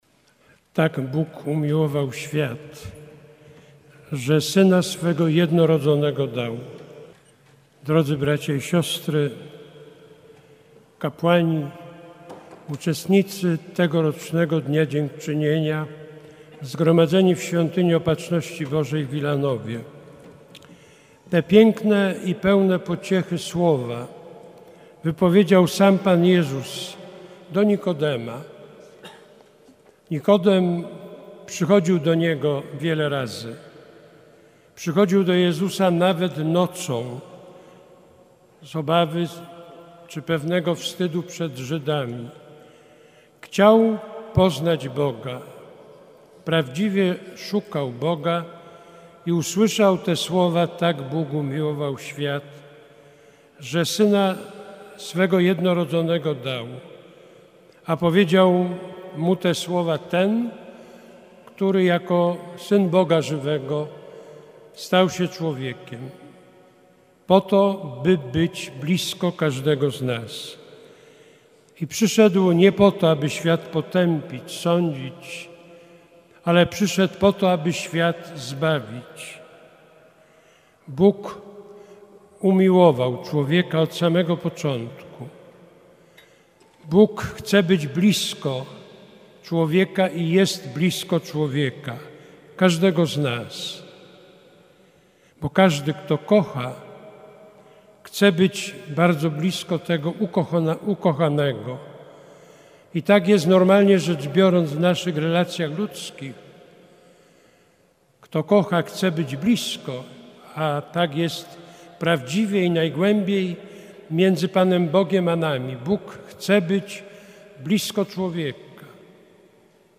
Metropolita warszawski 7 czerwca przewodniczył mszy świętej w Świątyni Opatrzności Bożej w Wilanowie. Była to Eucharystia odprawiona w ramach obchodów XIII Święta Dziękczynienia.